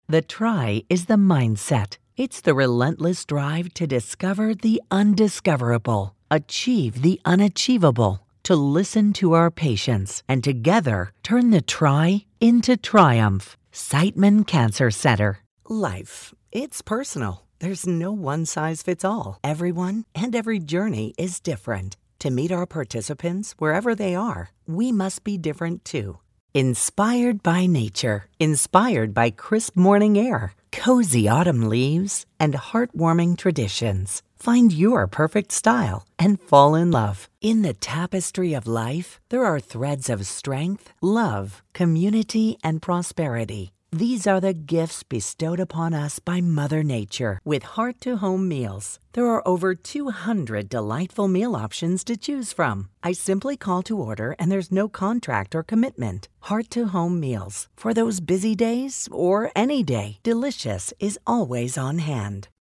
Female
Adult (30-50)
Natural Speak
Warm, Compassionate, Calm